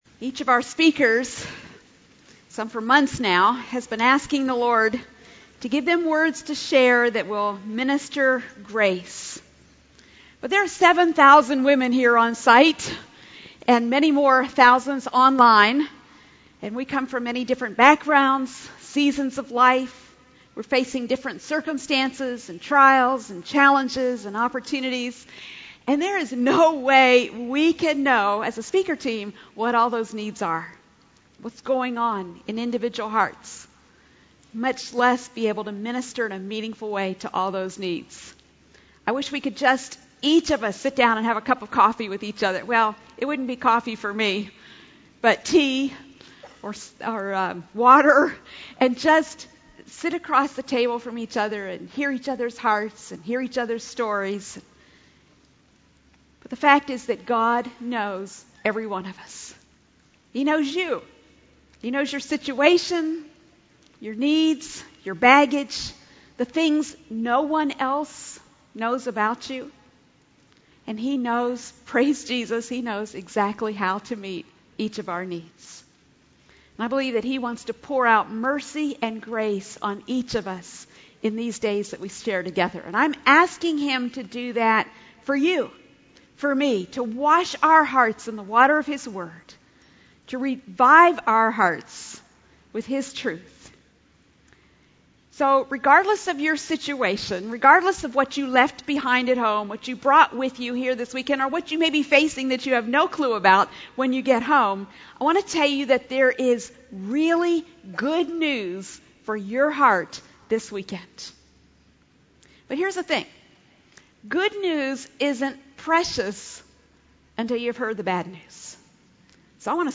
Message 1: The Power and Beauty of the Truth | True Woman '18 | Events | Revive Our Hearts